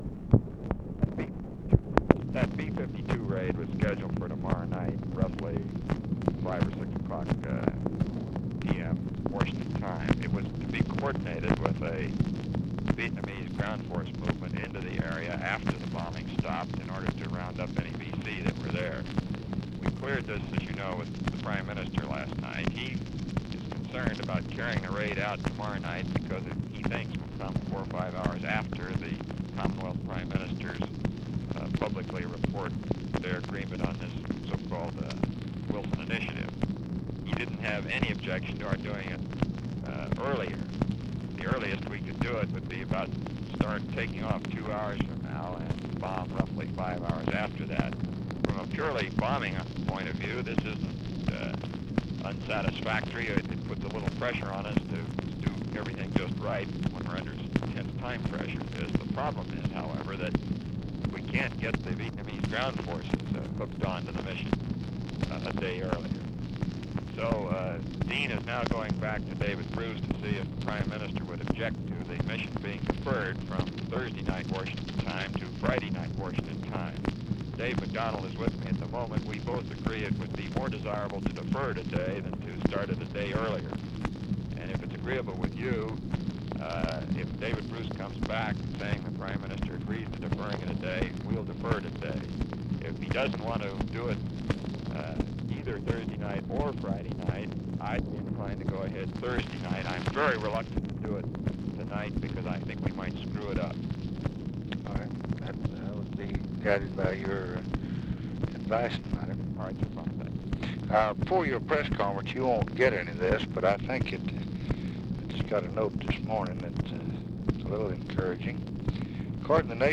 Conversation with ROBERT MCNAMARA, June 16, 1965